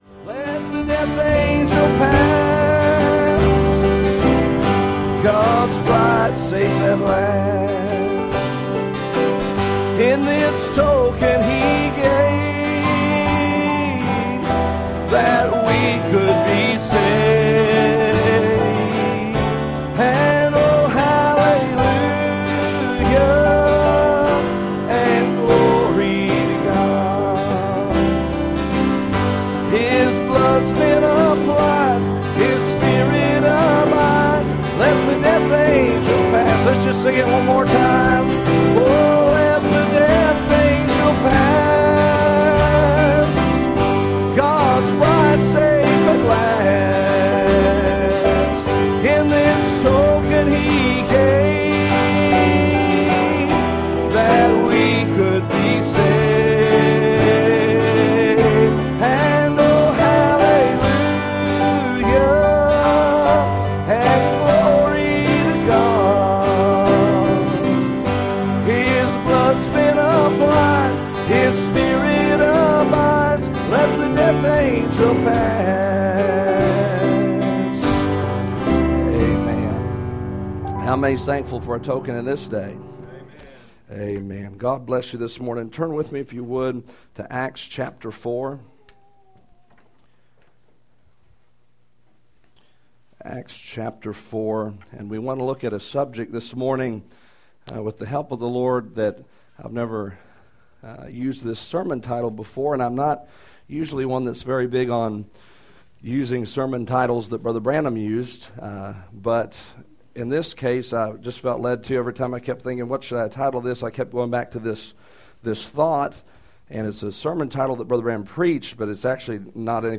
Passage: Acts 4:13 Service Type: Sunday Morning